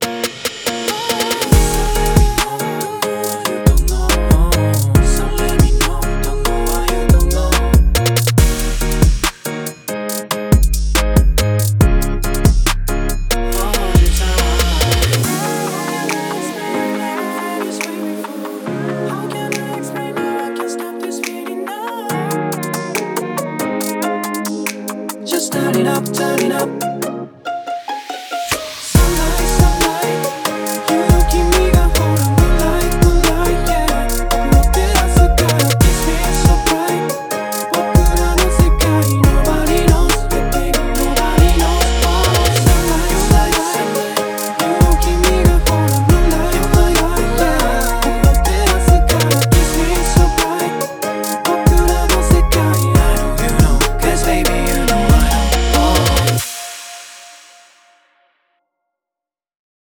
今回のオーディションのために制作されたオリジナル楽曲です。